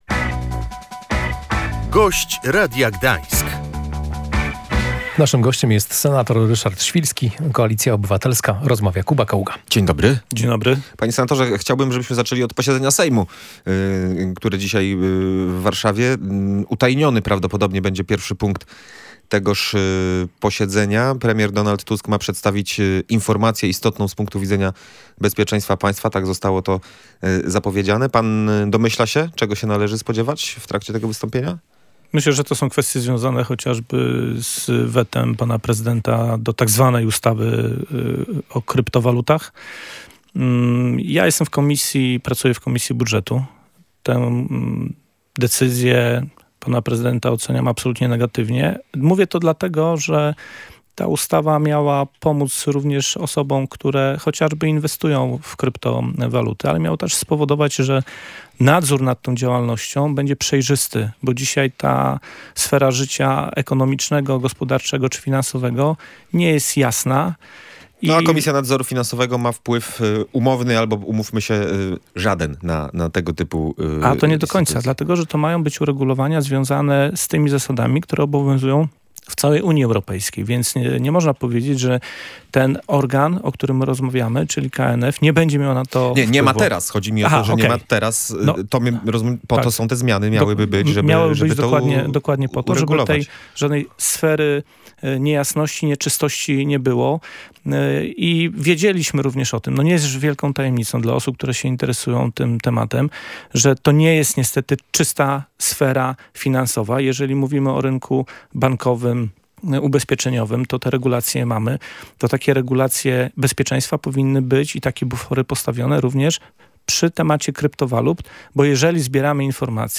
Dobiegają końca prace nad ustawą metropolitalną – zapewnił na antenie Radia Gdańsk senator Koalicji Obywatelskiej Ryszard Świlski.